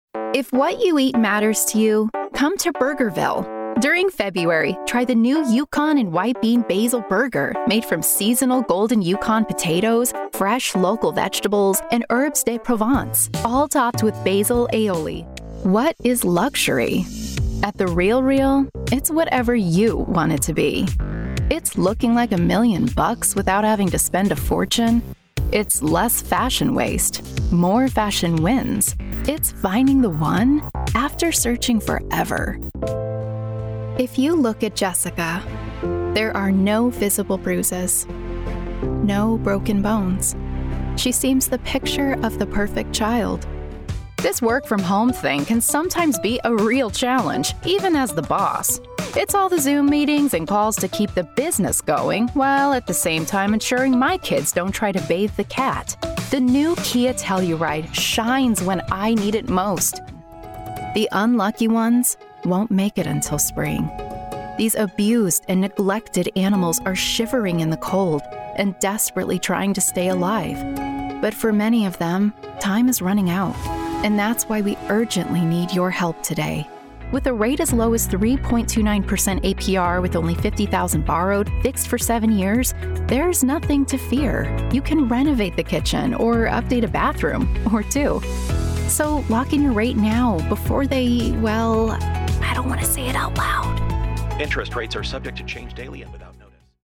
Voiceover Artist - Heartfelt Storytelling
Commercial Demo
Neutral English